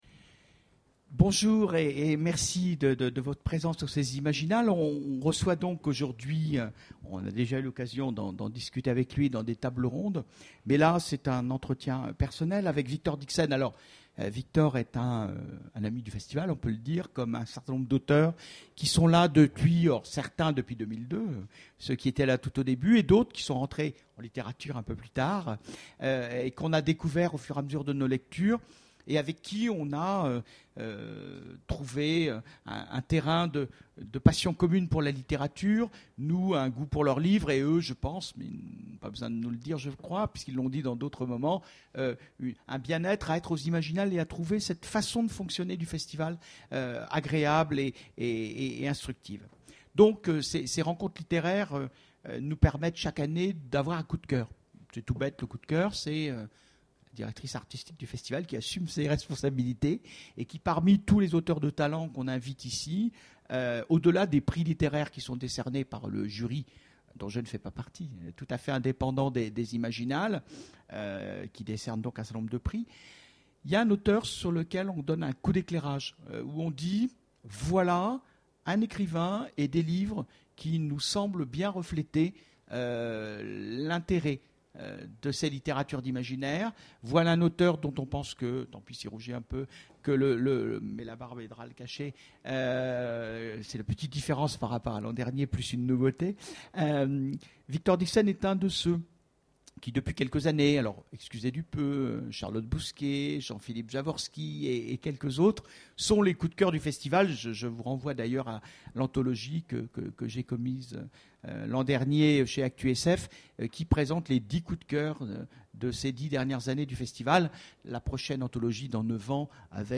Conférence
Rencontre avec un auteur